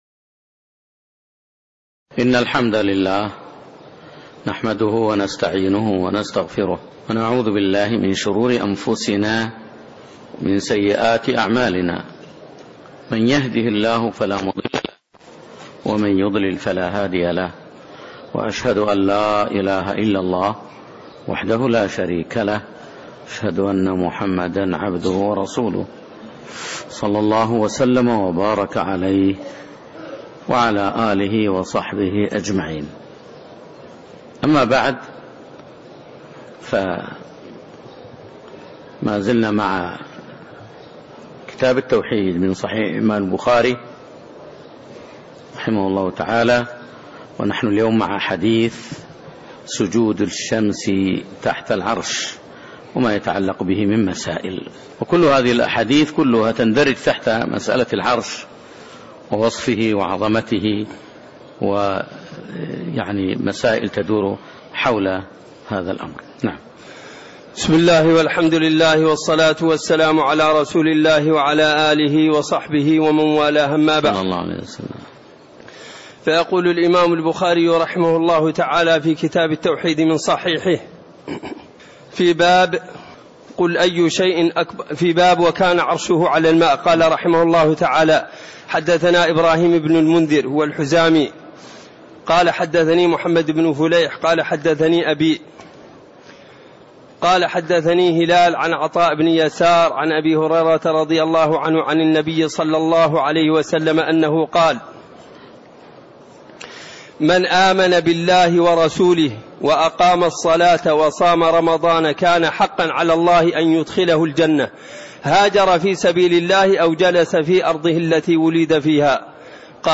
تاريخ النشر ٦ ربيع الثاني ١٤٣٤ هـ المكان: المسجد النبوي الشيخ